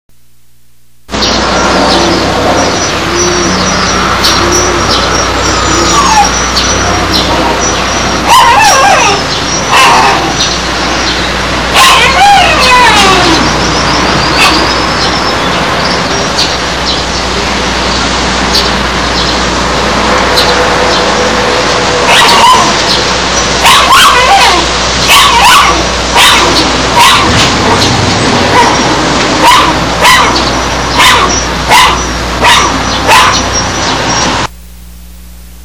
dog1993.wma